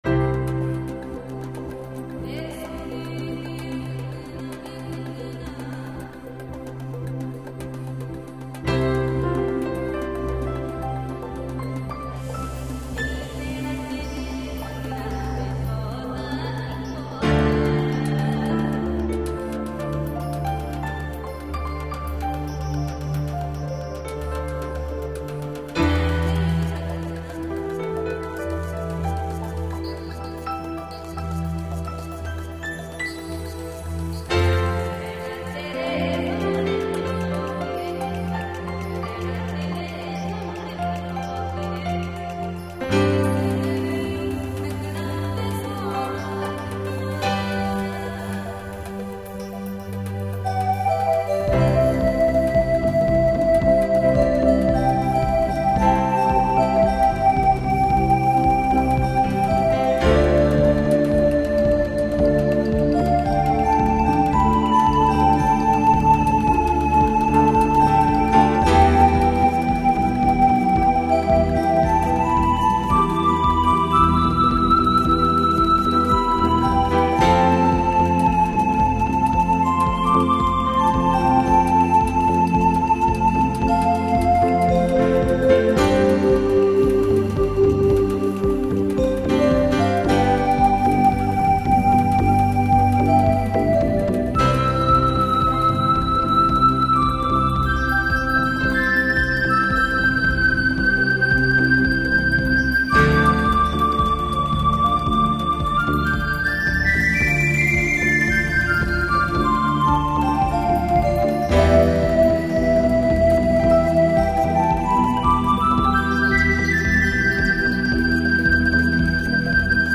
Die Musikrichtung geht primär in Richtung New Age/World und Jazz, aber hören Sie doch einfach mal rein.
Dieser Titel entstand weniger von der Melodiestimme her - diese wurde erst später hinzugefügt, sondern mehr von der Begleitung her. Die singende Dame am Anfang und Ende gab ihm dann aber seinen Namen.